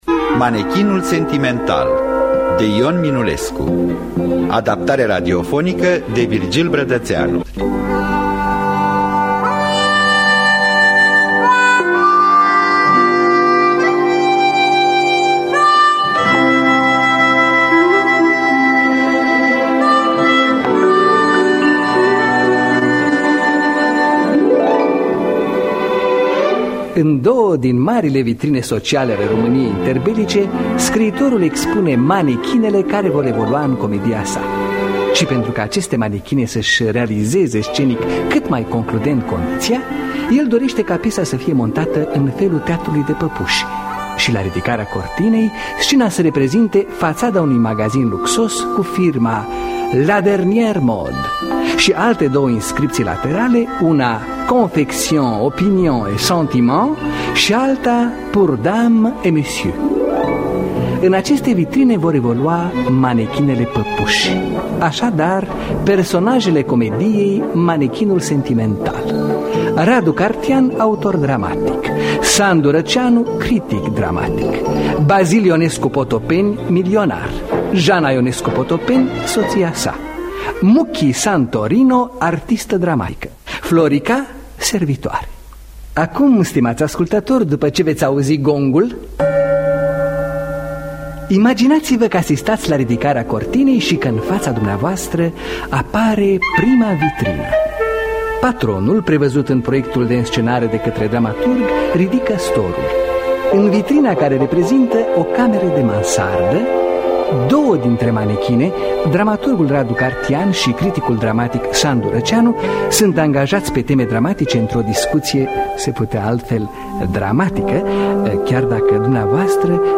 Adaptarea radiofonică de Virgil Brădăţeanu.